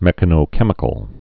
(mĕkə-nō-kĕmĭ-kəl)